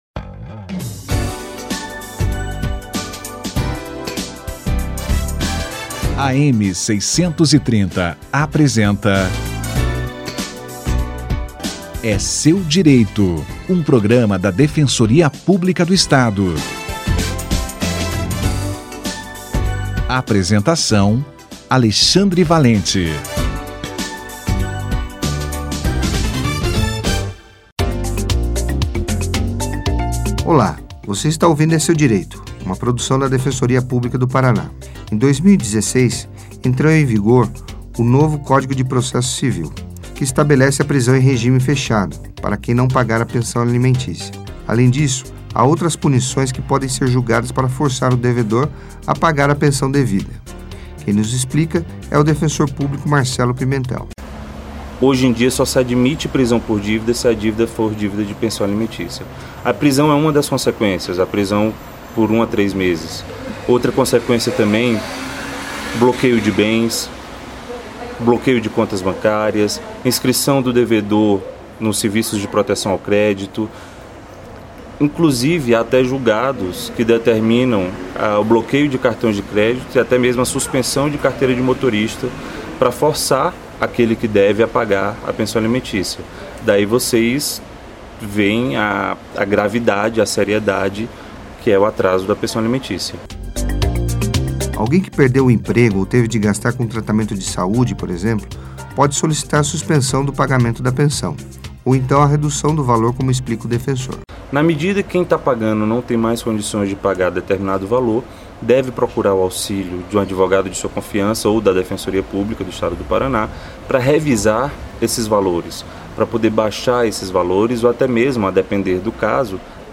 Punição ao não pagamento da Pensão Alimentícia - Entrevista